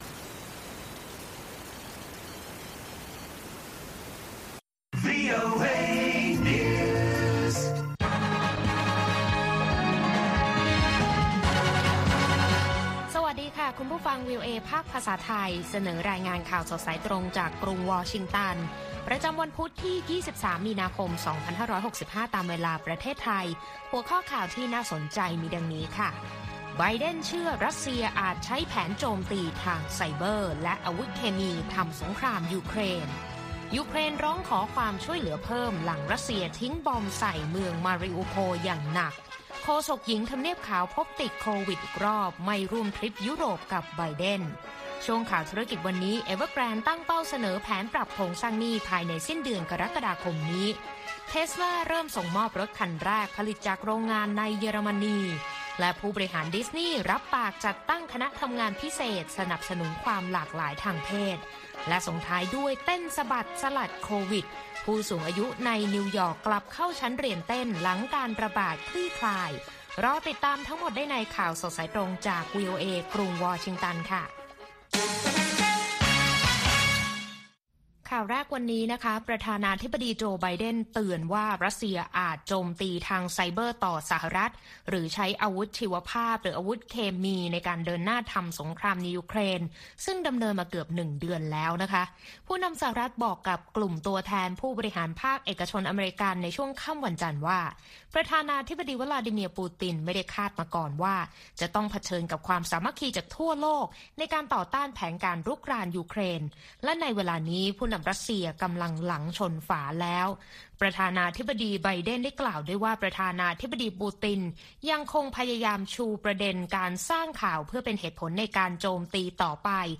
ข่าวสดสายตรงจากวีโอเอ ภาคภาษาไทย วันพุธ ที่ 23 มีนาคม 2565